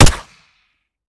Home gmod sound weapons papa90
weap_papa90_sup_fire_plr_01.ogg